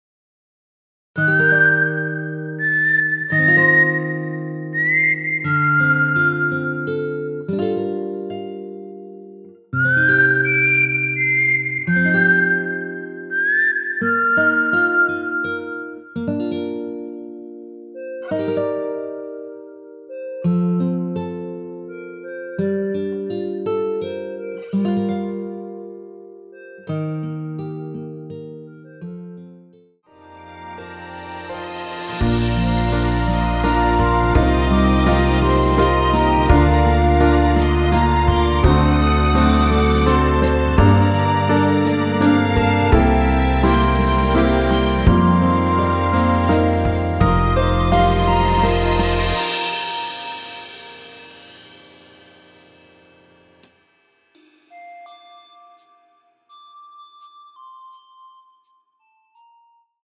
원키에서(+2)올린 멜로디 포함된 MR입니다.
Bb
앞부분30초, 뒷부분30초씩 편집해서 올려 드리고 있습니다.
중간에 음이 끈어지고 다시 나오는 이유는